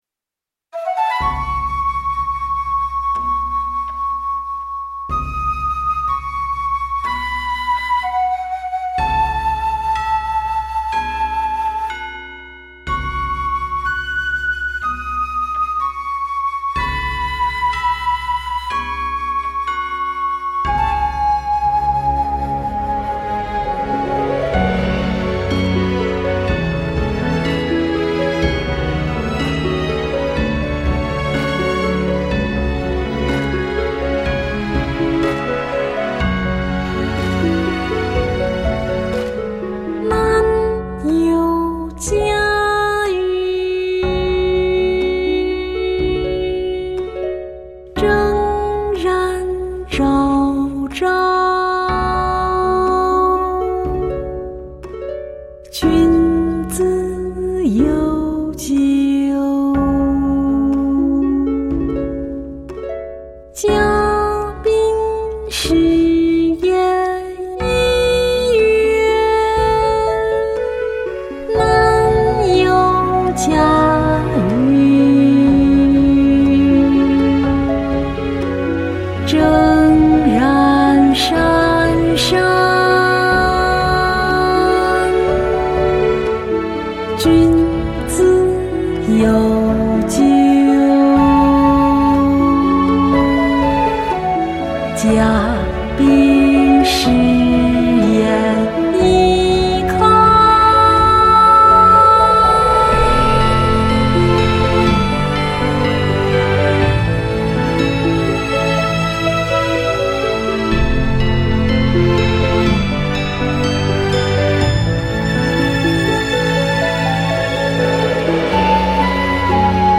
诗词吟诵
现场吟诵篇目一：